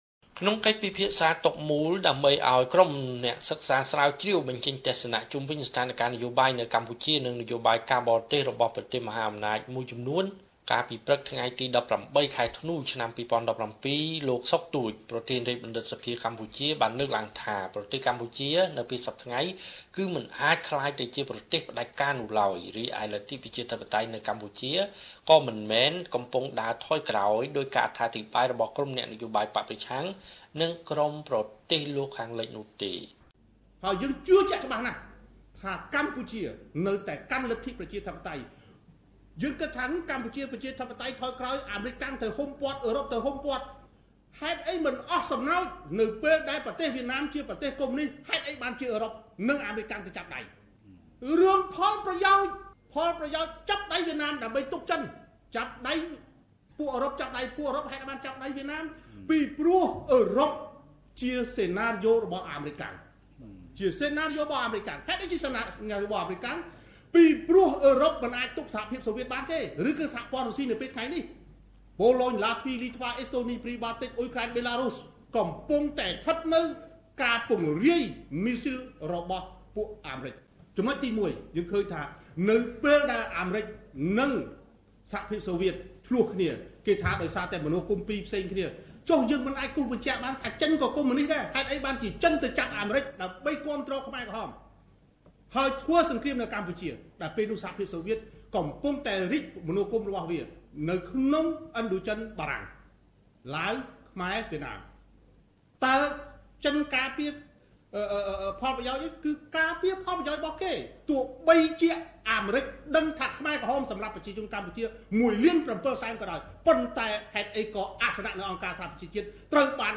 ក្នុងវេទិកាតុមូល ដើម្បីឲ្យក្រុមអ្នកសិក្សាស្រាវជ្រាវបញ្ចេញទស្សនៈ ជុំវិញស្ថានការនយោបាយ នៅកម្ពុជា និងនយោបាយការបរទេសរបស់ប្រទេសមហាអំណាចមួយចំនួន កាលពីព្រឹកថ្ងៃទី១៨ ខែធ្នូ ឆ្នាំ២០១៧ លោក សុខ ទូច ប្រធានរាជបណ្ឌិតសភាកម្ពុជា បានលើកឡើងថា ប្រទេសកម្ពុជា មិនអាចក្លាយជាប្រទេសផ្តាច់ការនោះឡើយ។